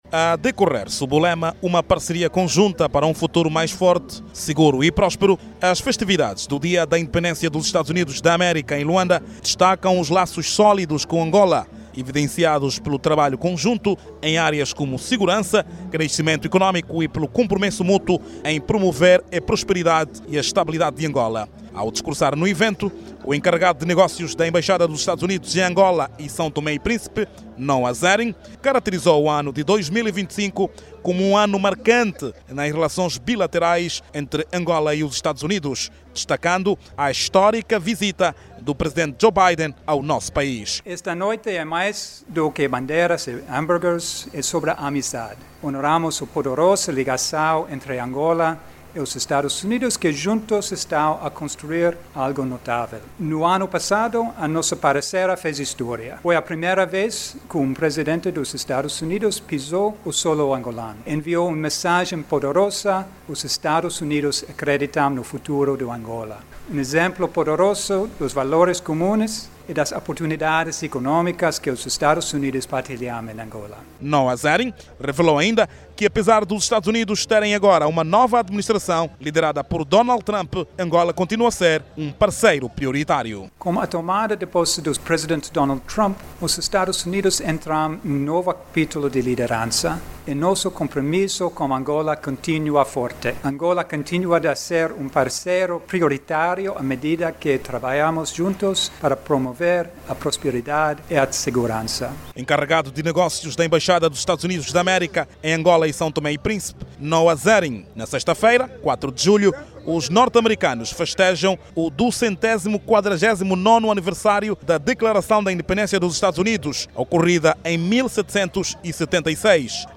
Os cidadãos norte-americanos residentes em Angola festejaram ontem, quarta-feira(02), de forma antecipada, o dia da independência dos Estados Unidos, cuja data oficial celebra-se amanhã, 04 de Julho. A Cerimónia oficial aconteceu na Embaixada dos Estados Unidos em Angola e São Tomé e Príncipe  em Luanda e, foi marcada por várias actividades.